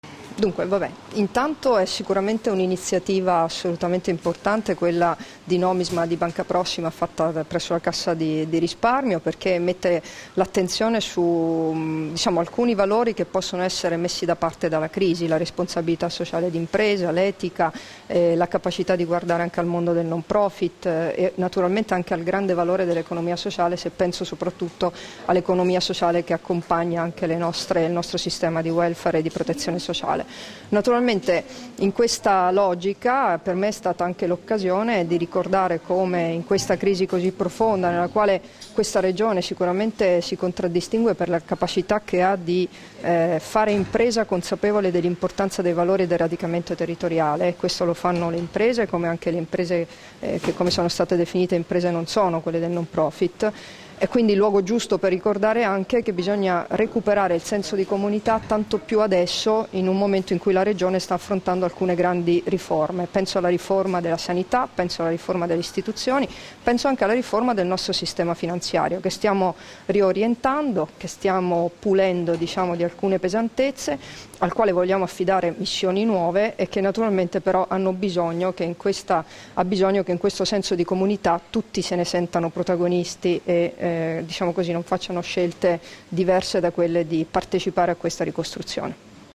Dichiarazioni di Debora Serracchiani (Formato MP3) alla presentazione dell'Accordo di collaborazione tra Nomisma e Banca Prossima, Banca del Gruppo Intesa Sanpaolo dedicata al no profit, nel corso del convegno ''Creare valore sociale, insieme'', rilasciate a Udine il 22 febbraio 2014 [1430KB]